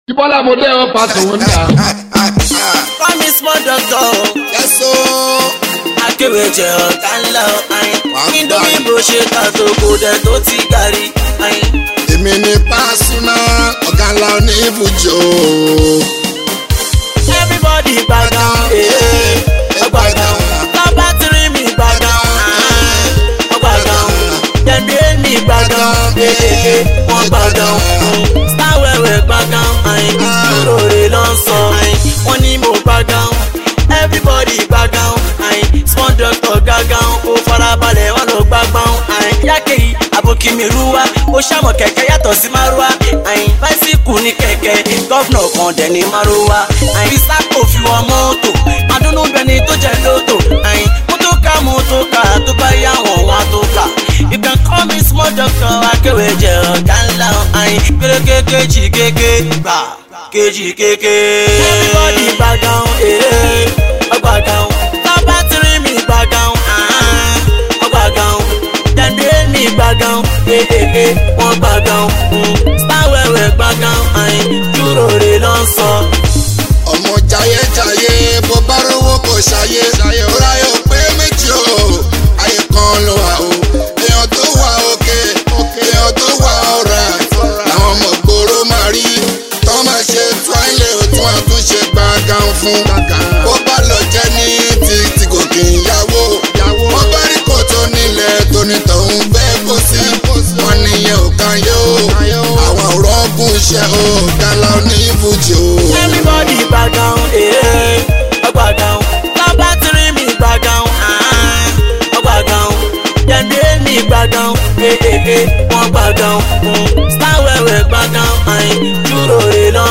Neo-Fuji, Pop, Yoruba Music